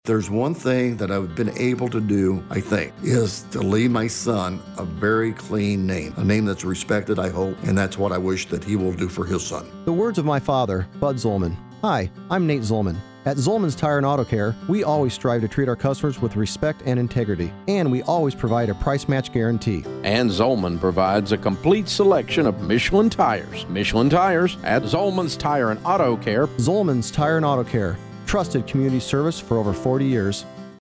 Through the “Trusted” campaign Omega Group crafted scripts for noted author Dave Ramsey to record.